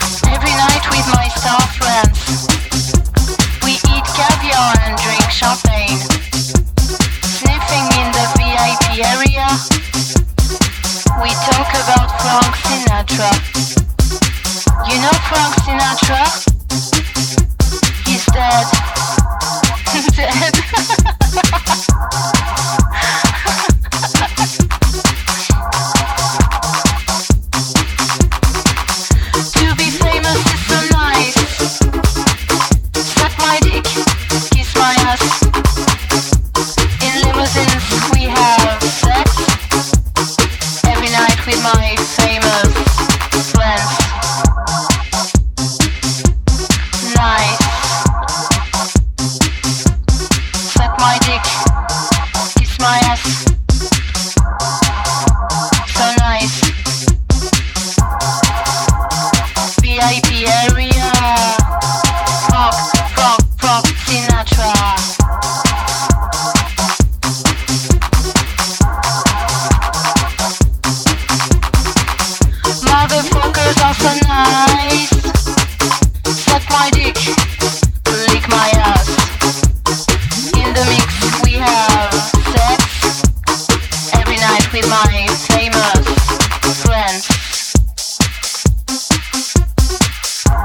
FOLK / FOLK ROCK / 60'S ROCK